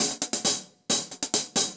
UHH_AcoustiHatC_135-04.wav